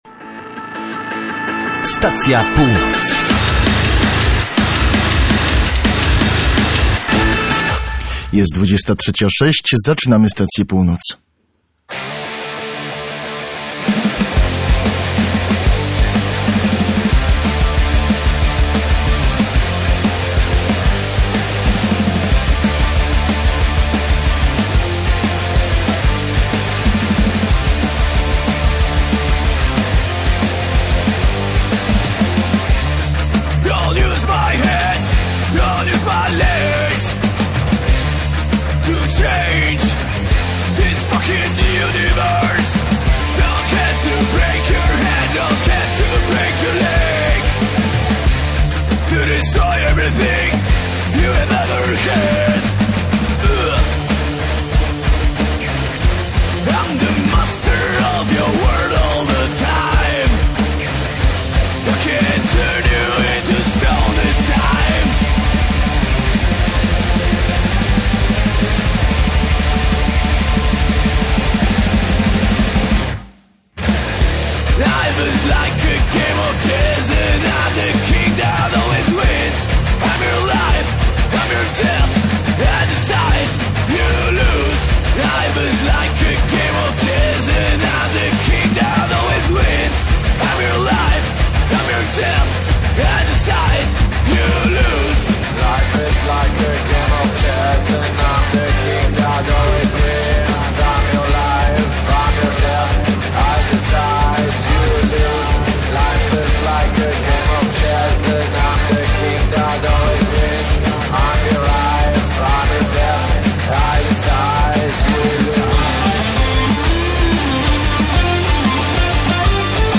Muzycy zespołu Medebor podsumowują i wspominają ponad 20 lat grania